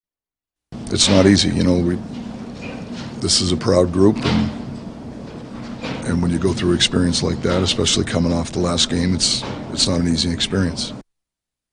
Coach Mike Sullivan reacted to a second straight blowout loss.